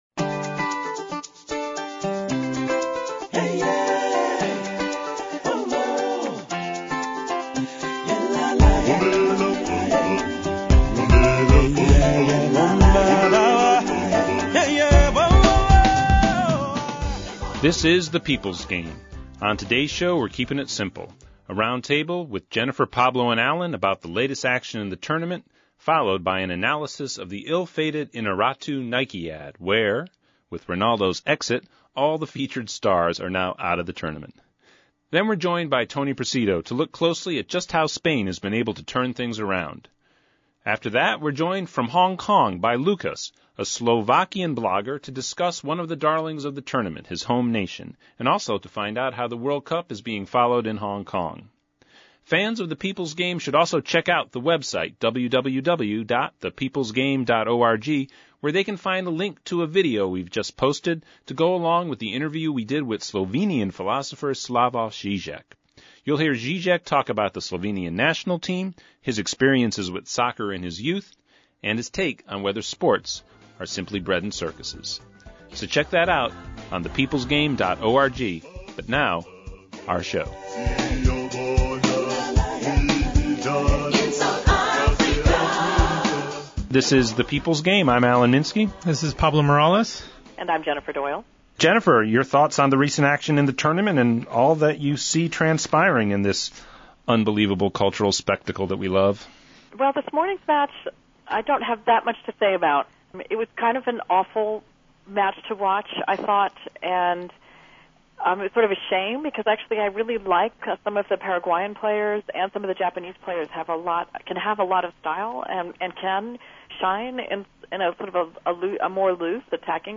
The People’s Game Radio Show for Tuesday June 29, 2010 We keep it simple today with a roundtable discussion about the latest games and the ill-fated Inarritu Nike add where, with Ronaldo’s exit, all the featured stars are now exiled from the mundial.